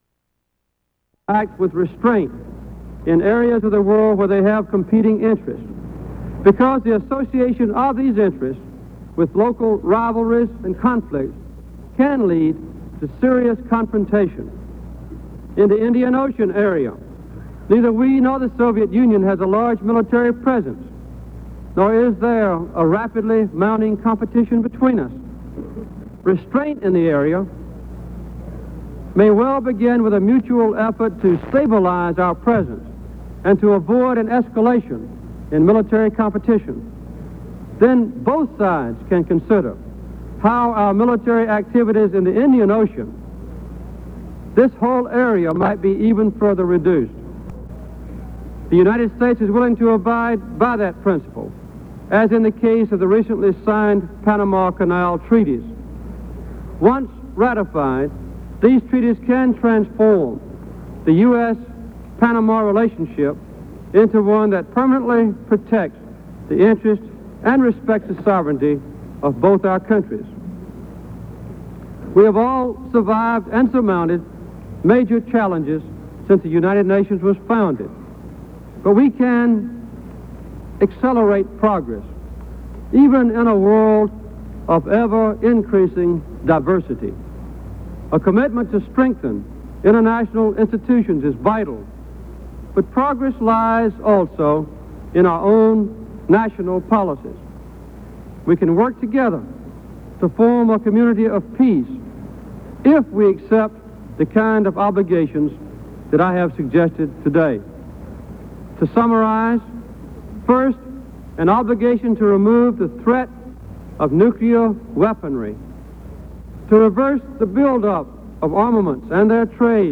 Jimmy Carter addresses the United Nations General Assembly
General Assembly Subjects Disarmament Nuclear disarmament Material Type Sound recordings Language English Extent 00:06:00 Venue Note Broadcast on NPR, October 4, 1977.